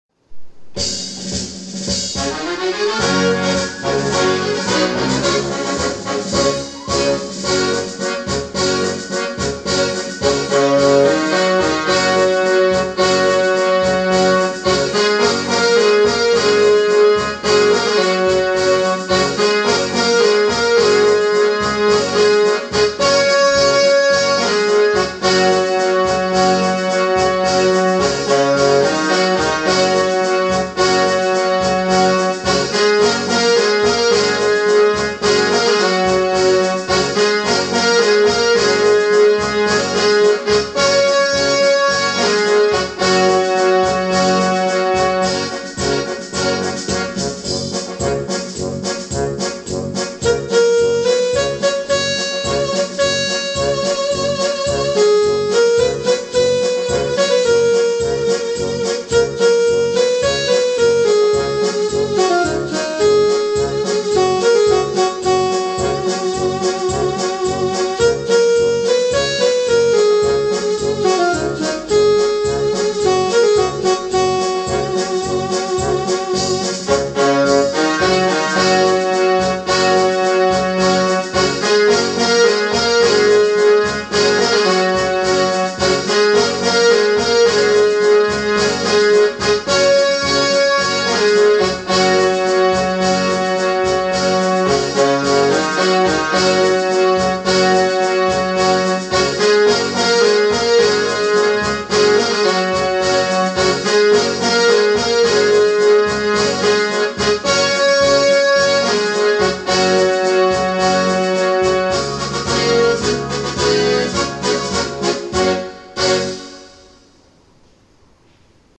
INSTRUMENTAL Section